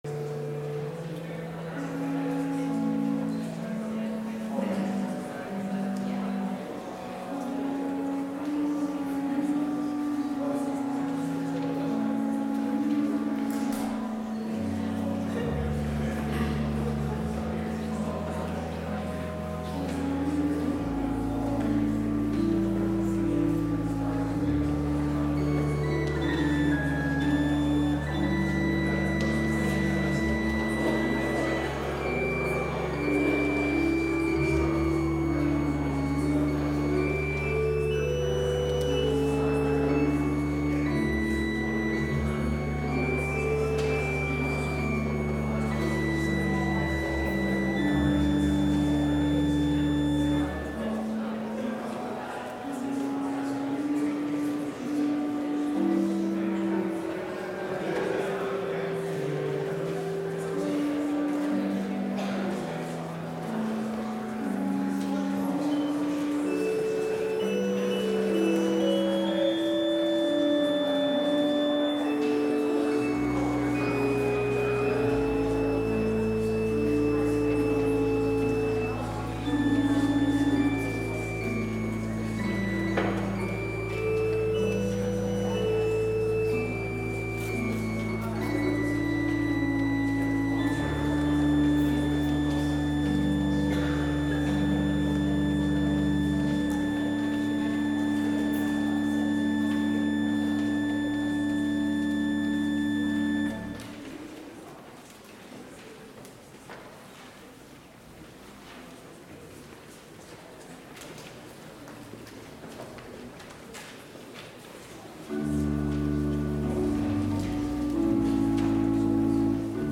Complete service audio for Chapel - January 11, 2022